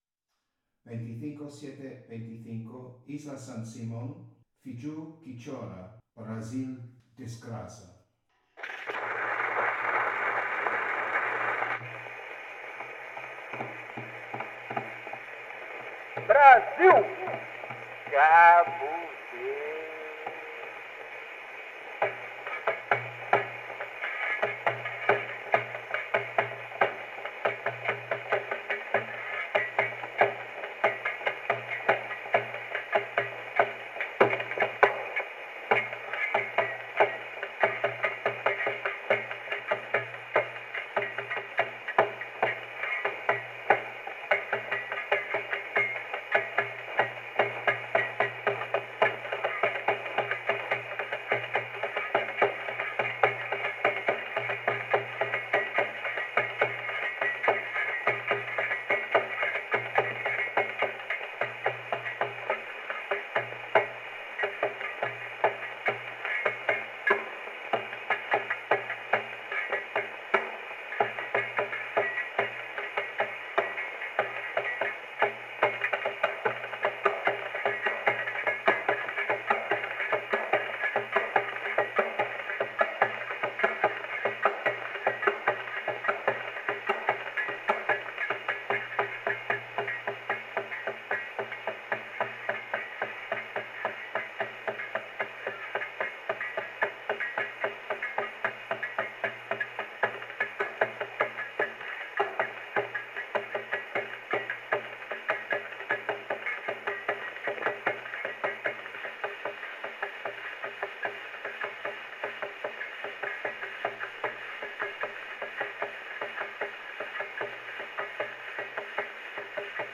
Original sound from the phonographic cylinder:
Recording place: Espazo Cafetería